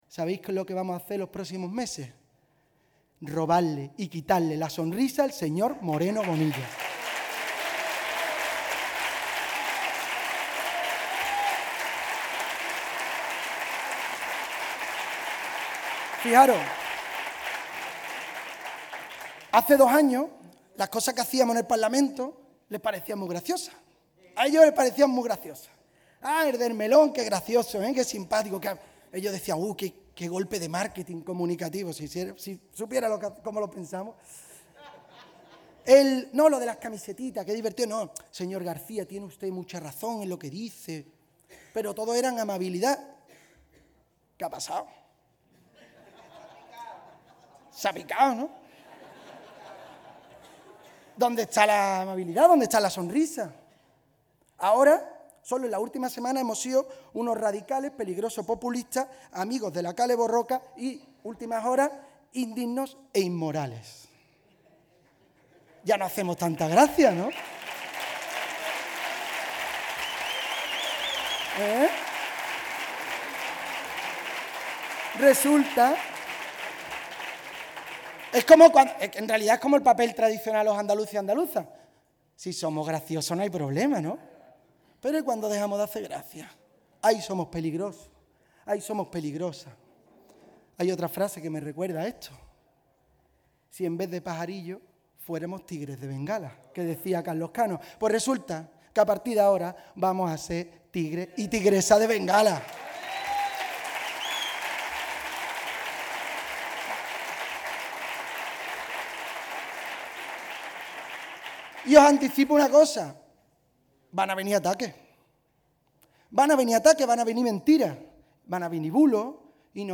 La formación andalucista de izquierdas, Adelante Andalucía, da el pistoletazo de salida al curso electoral con un mitin de su candidato, José Ignacio García, tras el exitoso encuentro celebrado en Cuevas del Becerro (Málaga).
corte-jose-ignacio-cierre-mitin.mp3